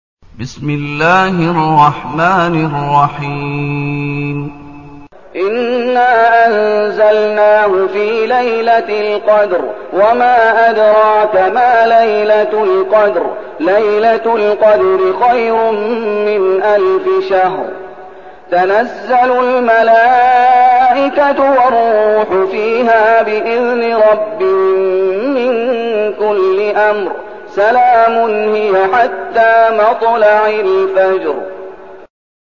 المكان: المسجد النبوي الشيخ: فضيلة الشيخ محمد أيوب فضيلة الشيخ محمد أيوب القدر